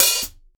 Closed Hats
TC3Hat7.wav